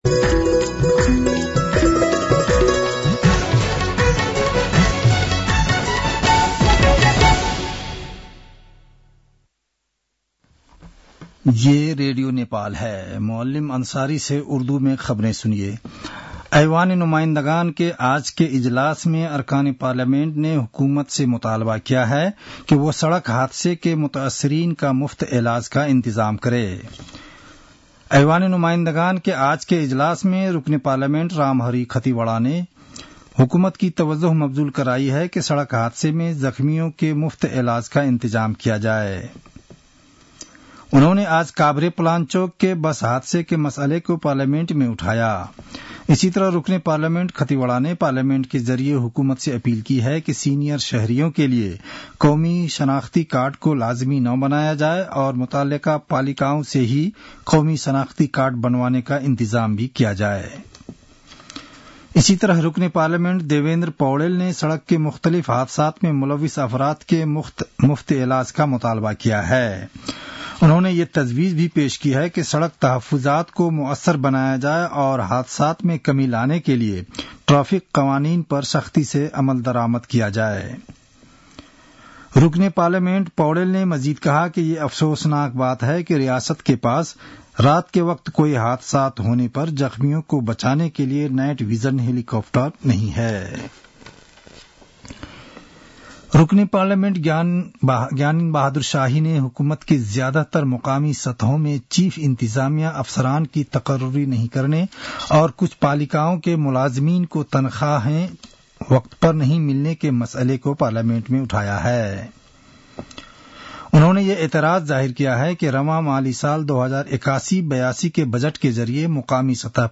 उर्दु भाषामा समाचार : १४ फागुन , २०८१